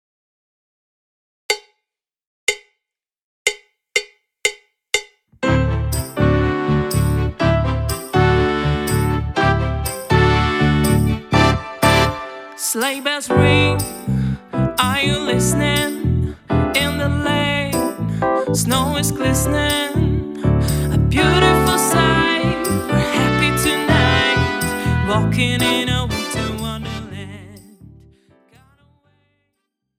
Besetzung: Schlagzeug